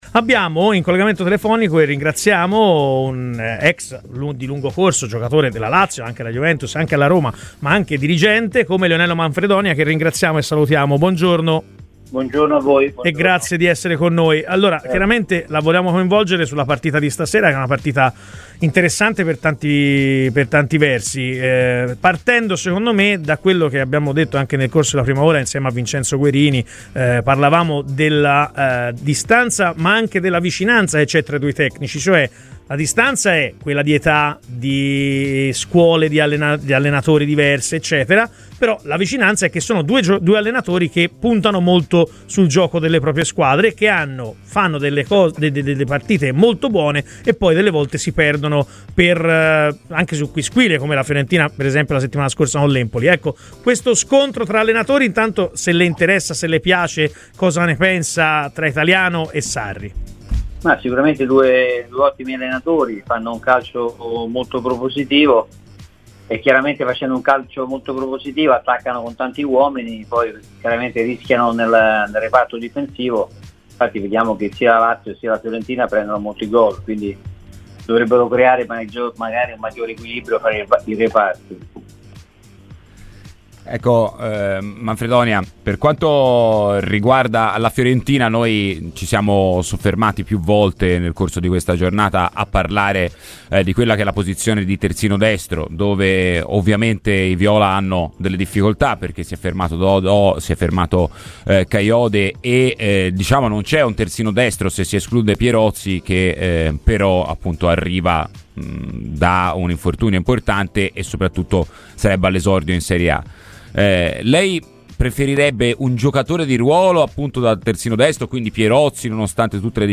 Lionello Manfredonia, ex giocatore e dirigente, ha parlato oggi a Radio Firenzeviola durante 'Chi si compra'.